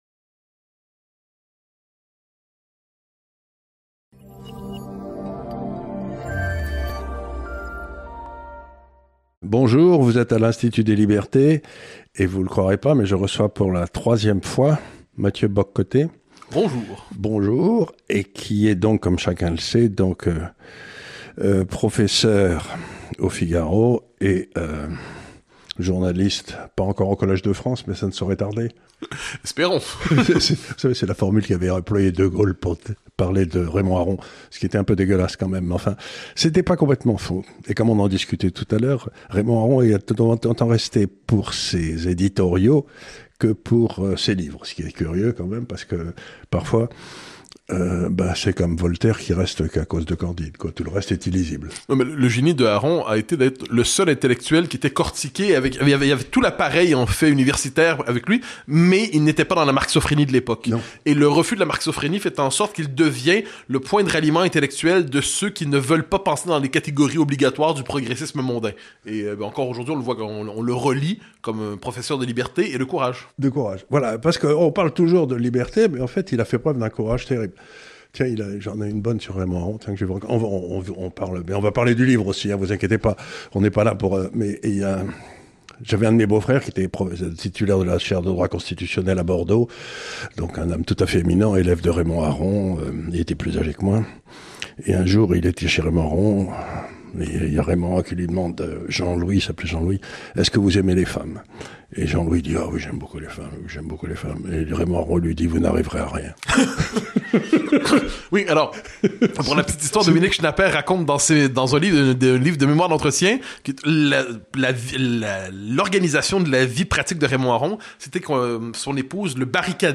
#164 - Les Deux Occidents, Mathieu Bock-Côté est l'invité de Charles Gave.